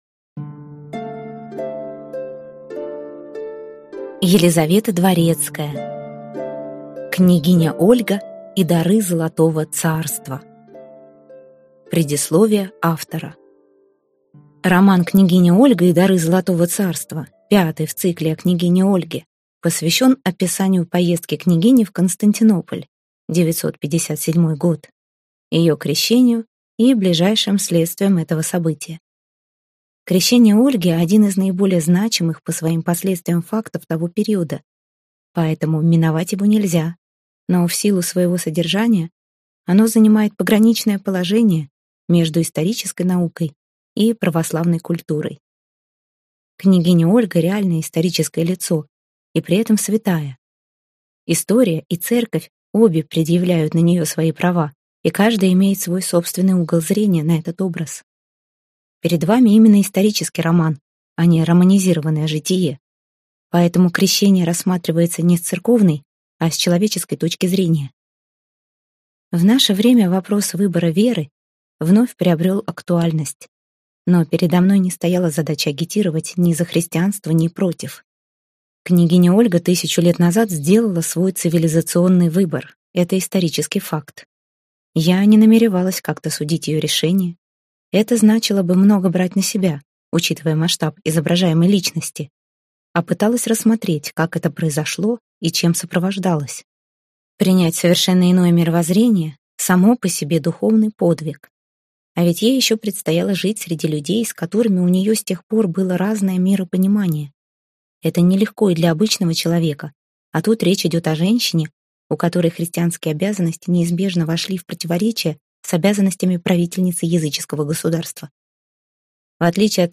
Аудиокнига Княгиня Ольга и дары Золотого царства | Библиотека аудиокниг